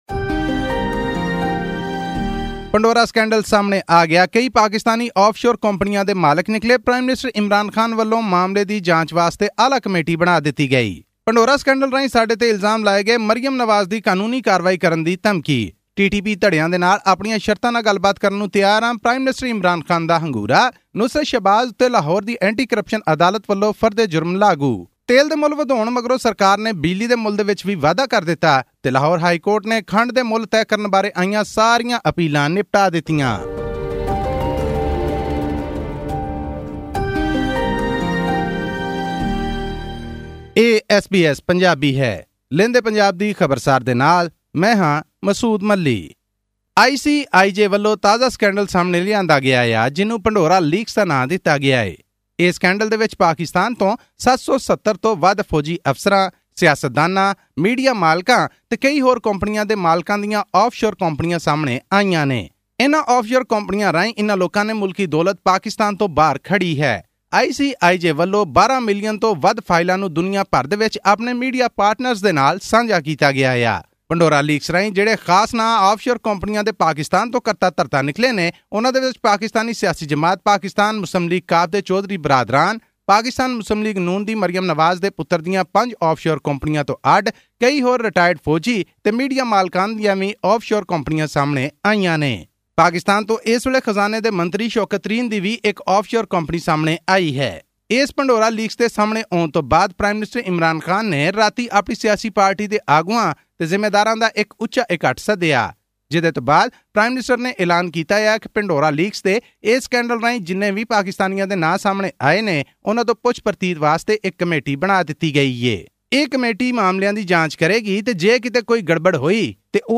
Prime Minister Imran Khan has vowed to investigate over 700 high-profile Pakistani citizens named in the global media investigation on hidden global wealth dubbed the Pandora Papers. All this and more in our news bulletin from Pakistan.